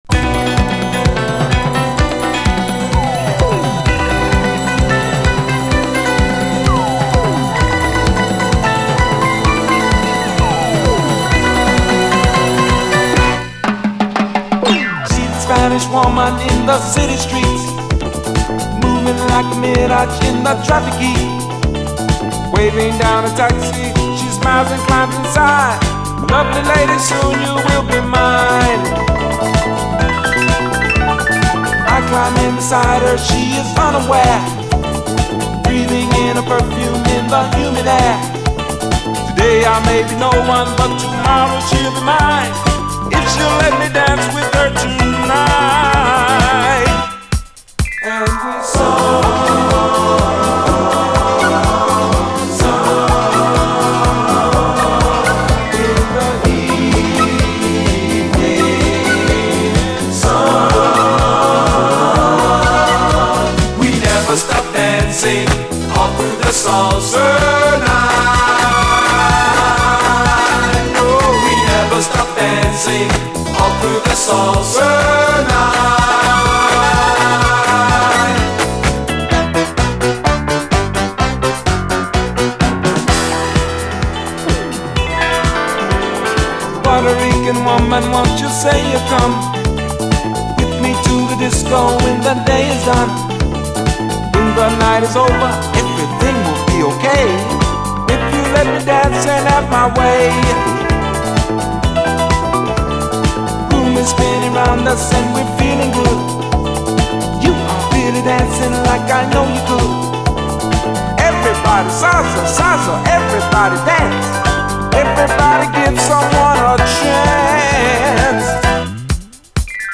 SOUL, 70's～ SOUL, DISCO, 7INCH
ブリット・ファンク・バンドが残したB級ラテン・ディスコ45！
熱きラテン・グルーヴ、そして浮遊するコーラスへの展開がよい！